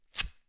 Open Window School Of Visual Communication " 粘扣带快速撕裂术
描述：记录在TASCAM ZOOM H4n上，XY胶囊设置为90°。关闭透视单声道录音。 魔术贴撕裂的速度非常快。
Tag: 运动 翻录 快速运动 魔术贴 运动 口吃 OWI